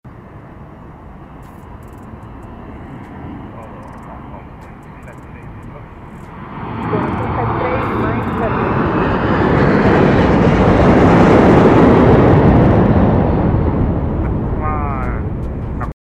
Linda Decolagem do Aeroporto de sound effects free download